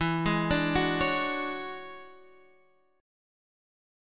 E13 Chord
Listen to E13 strummed